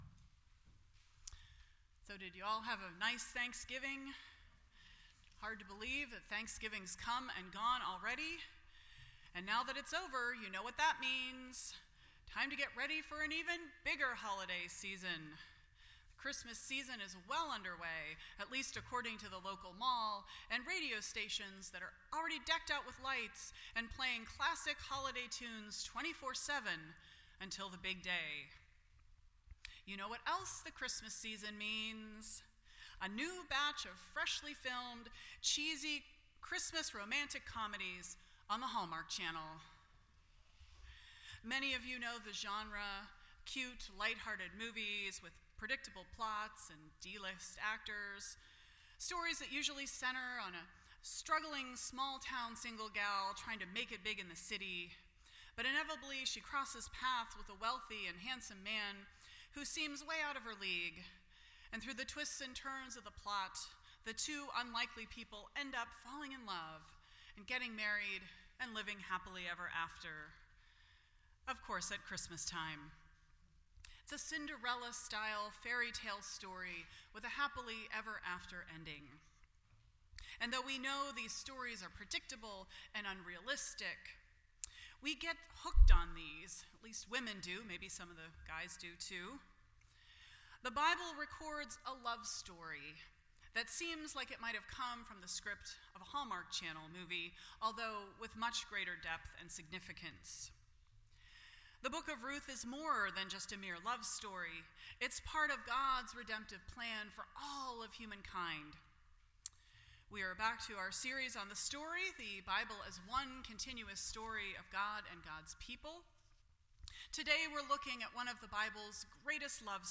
The Story Service Type: Sunday Morning %todo_render% Share This Story